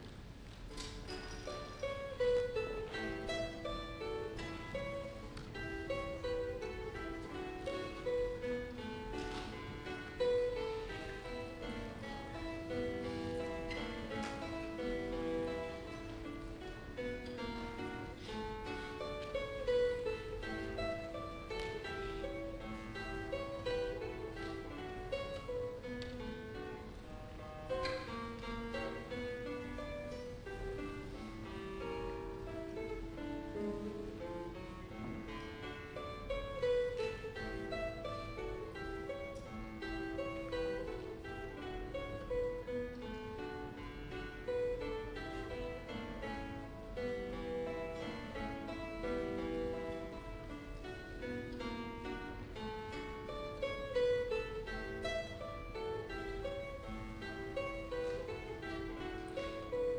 1992年12月18日　於 市民プラザ アンサンブルホール
独奏1